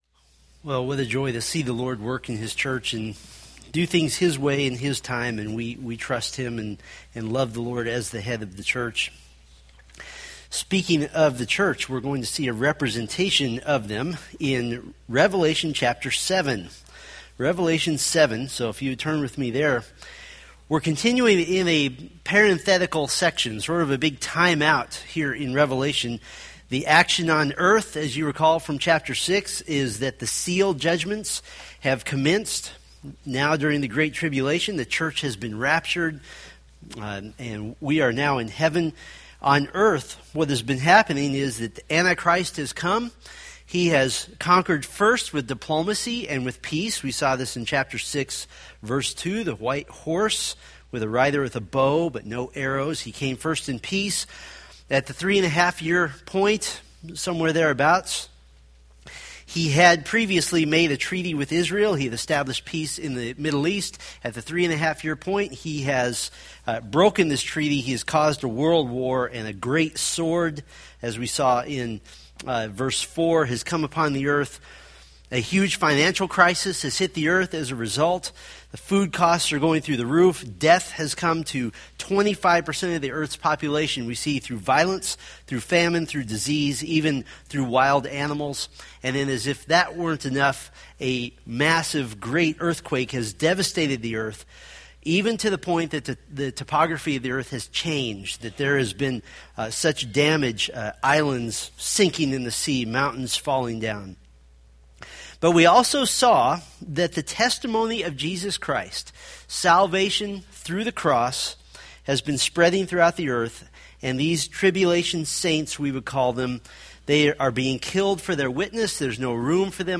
Revelation Sermon Series